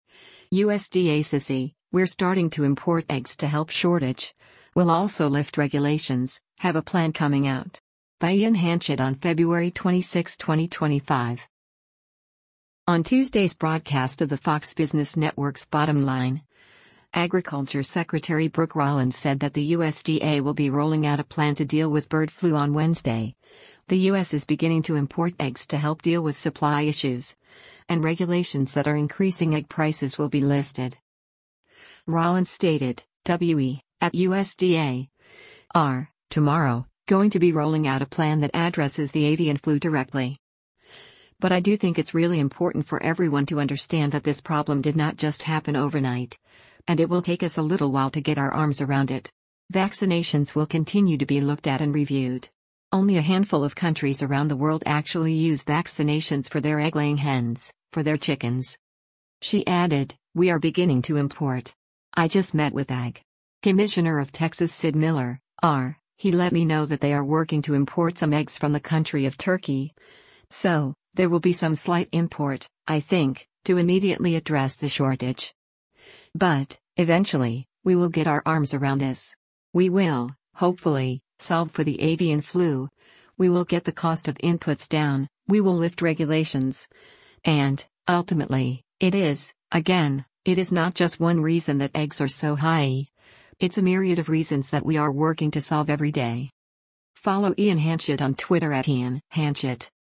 On Tuesday’s broadcast of the Fox Business Network’s “Bottom Line,” Agriculture Secretary Brooke Rollins said that the USDA will be rolling out a plan to deal with bird flu on Wednesday, the U.S. is beginning to import eggs to help deal with supply issues, and regulations that are increasing egg prices will be listed.